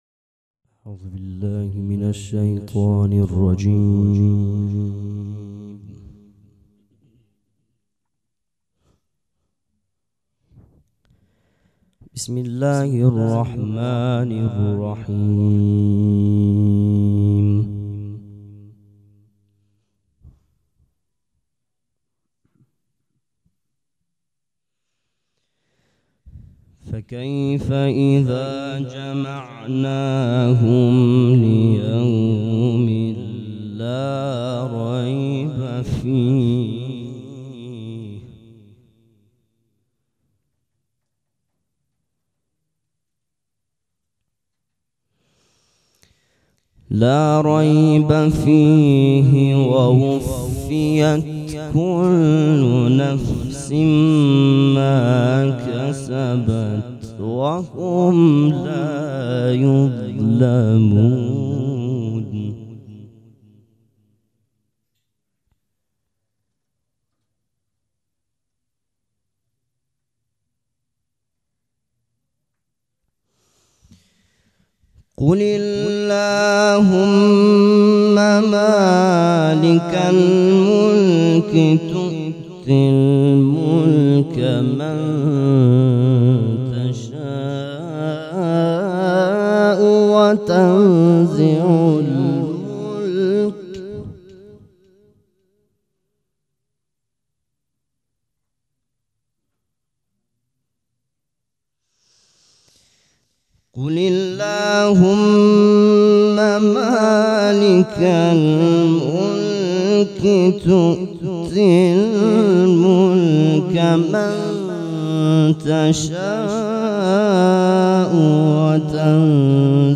قرائت قرآن کریم
دانلود تصویر قرائت قرآن کریم favorite مراسم مناجات شب بیستم ماه رمضان قاری
سبک اثــر قرائت قرآن